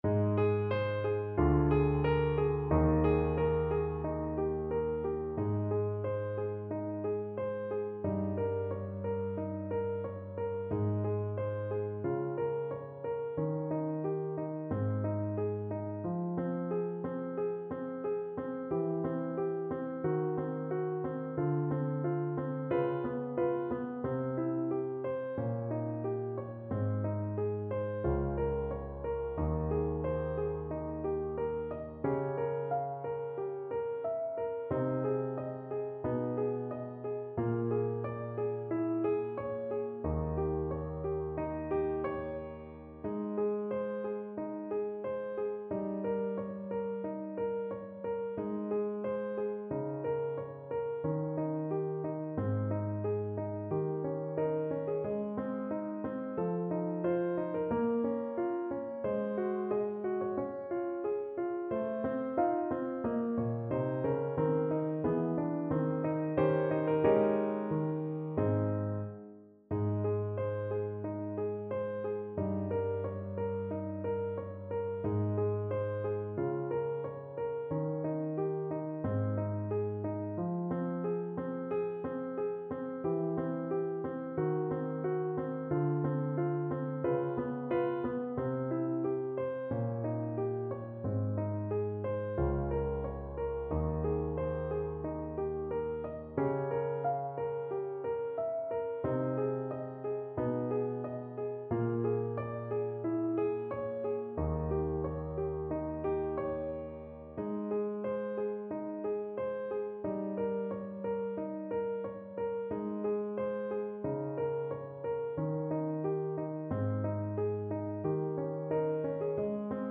Piano Playalong MP3